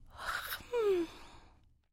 Звуки потягивания
Потягивание женской фигуры